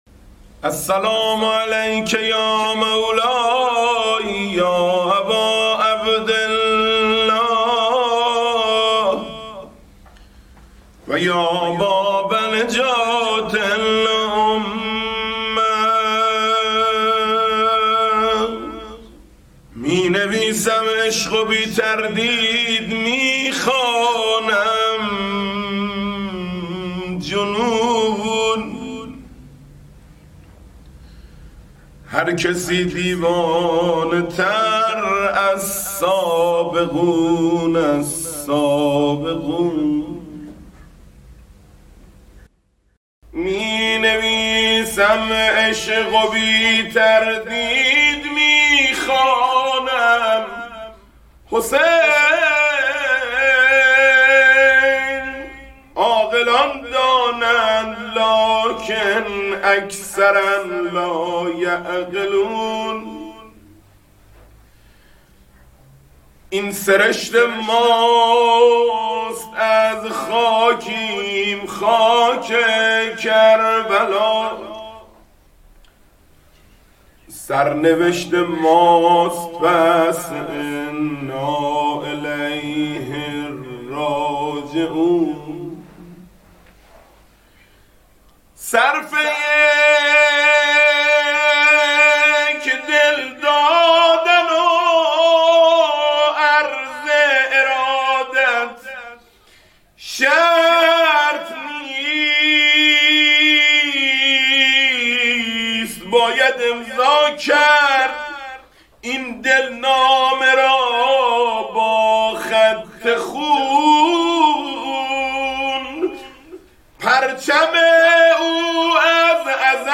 مولودی | می‌نویسم عشق و بی تردید می‌خوانم ، جنون
مداحی
مناسبت ولادت حضرت عباس (ع)